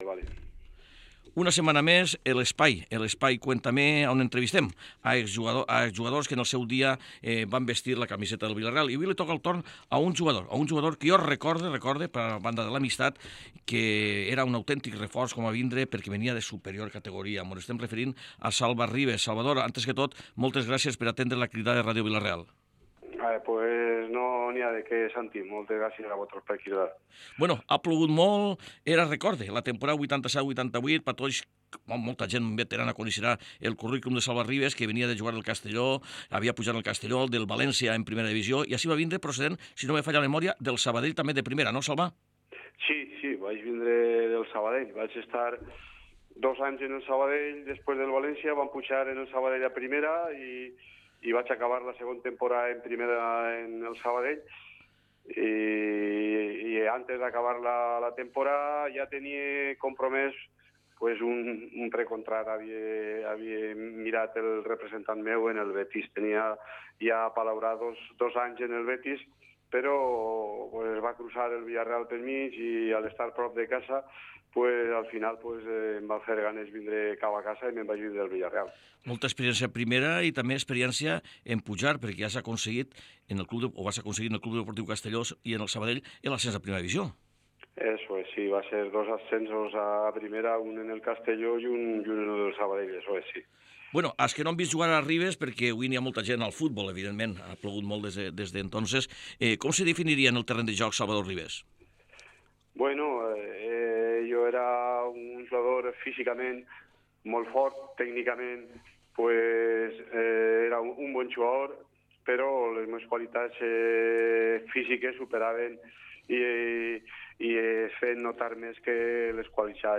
Cuéntame: Entrevista